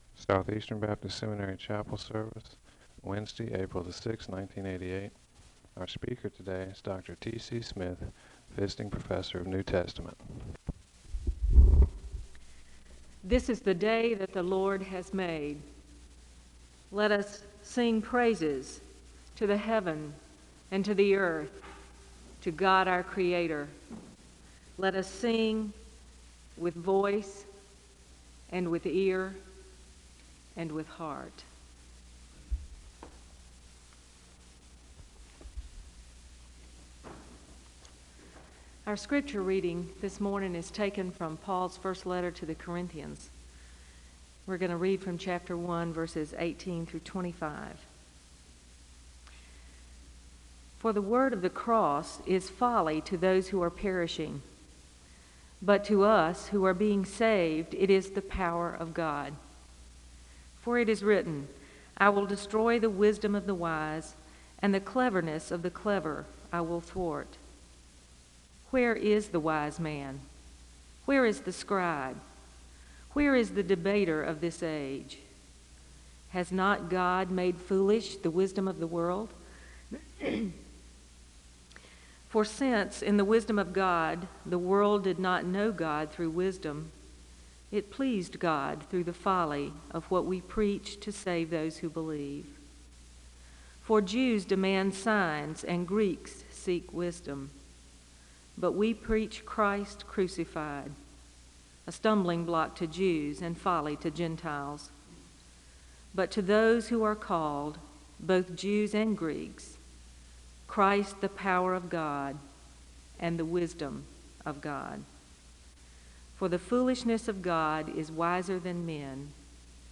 The service begins with a call to worship (0:00-0:35). There is a Scripture reading from I Corinthians (0:36-2:05). Prayer concerns are shared and there is a moment of prayer (2:06-4:23).
The service concludes with a moment of prayer (26:23-26:55).
Location Wake Forest (N.C.)